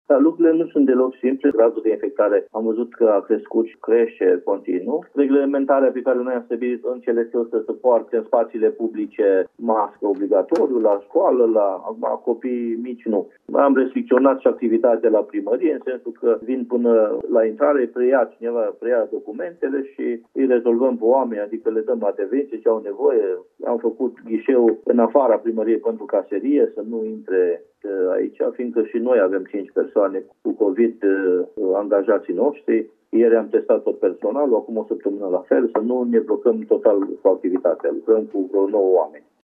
În plus, din cauza numărului mare de infectări, în fața primăriei a fost deschis un ghișeu separat pentru preluarea solicitărilor, spune primarul Viorel Mărcuți.